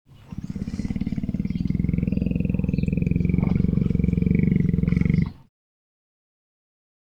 Index of /90_sSampleCDs/E-MU Producer Series Vol. 3 – Hollywood Sound Effects/Water/Lions